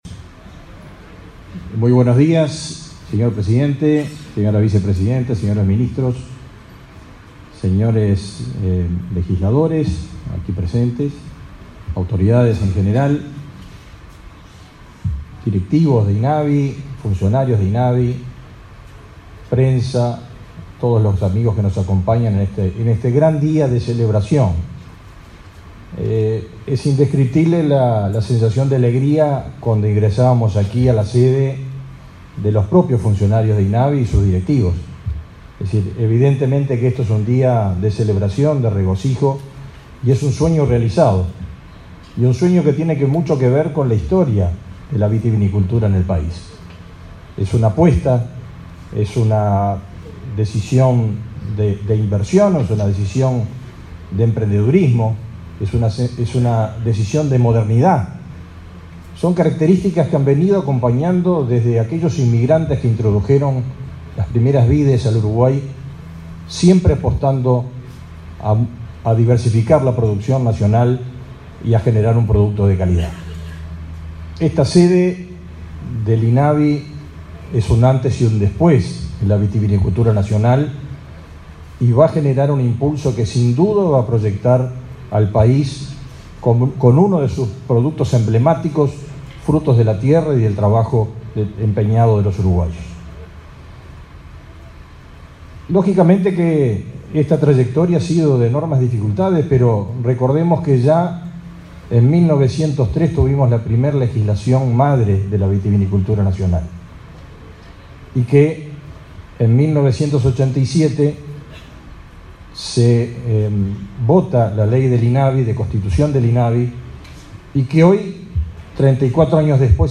Palabras del ministro de Ganadería y el presidente de Inavi
Palabras del ministro de Ganadería y el presidente de Inavi 21/06/2022 Compartir Facebook X Copiar enlace WhatsApp LinkedIn El ministro de Ganadería, Fernando Mattos, y el presidente del Instituto Nacional de Vitivinicultura (Inavi), Ricardo Cabrera, disertaron en la inauguración de la sede del instituto, ubicada en la localidad de Las Piedras, Canelones.